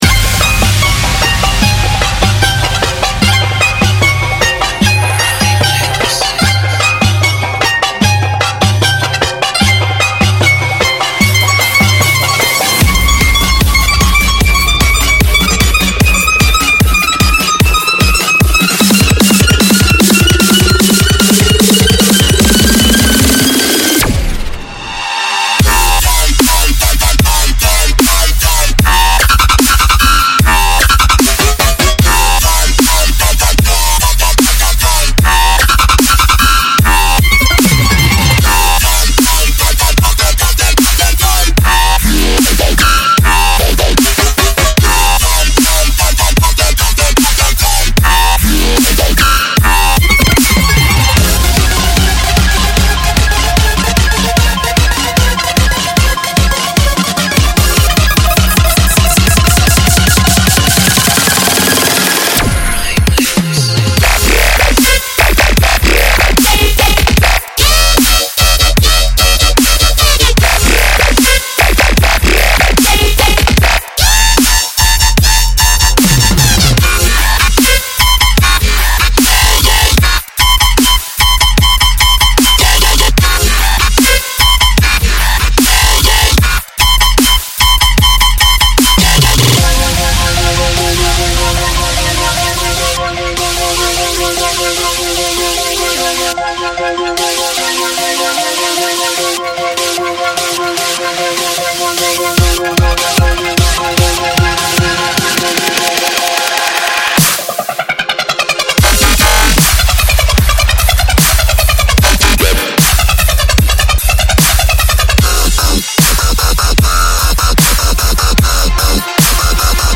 受诸如Getter，Riot Ten，Borgore，Megalodon，MUST DIE！，Snails，Funtcase，Bear Grillz，Zomboy，Ozi博士等艺术家的启发，我们的专家合成大师已在实验室中进行了大量工作，以为您带来Hybrid Dubstep：血清预设–专为Xfer的血清合成器而设计的超重低音预设集合！
“ Hybrid Dubstep：Serum Presets”为您的DAW带来了巨大的声波能量，它具有不断增长的低音，强劲的主角，郁郁葱葱的护垫，俏皮的Arps和令人兴奋的FX。
42 x Bass Presets
4 x FX